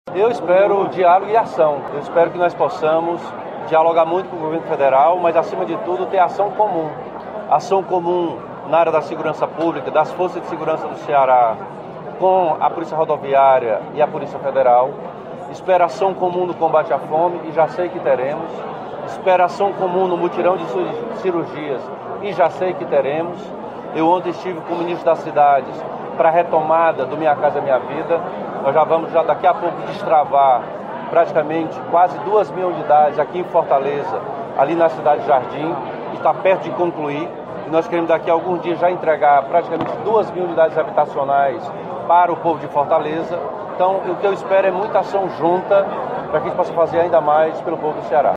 O governador Elmano de Freitas realizou, na manhã desta quinta-feira (2), na Assembleia Legislativa do Ceará (Alece), a leitura da Mensagem Governamental.
O governador Elmano de Freitas falou sobre parcerias com o Governo Federal para a retomada de importantes programas em diversas áreas do Governo.